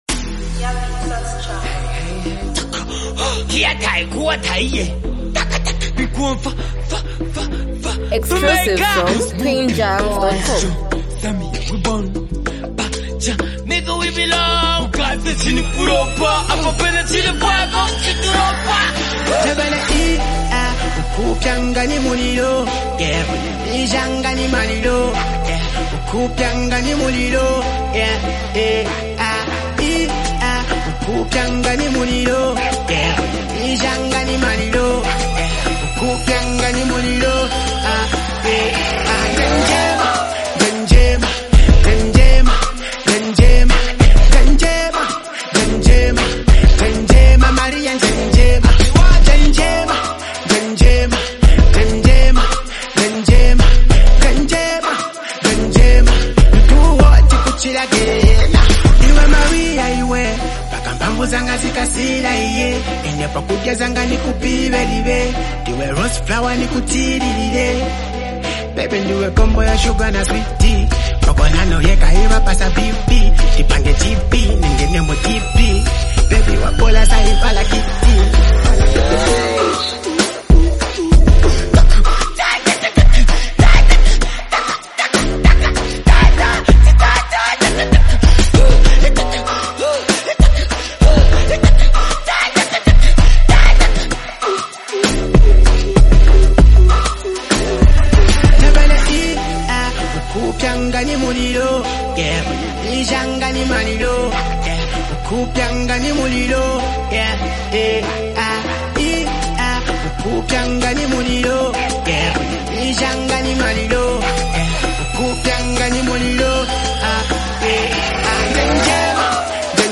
leads with a bold and playful delivery
add extra flavor with their distinct flows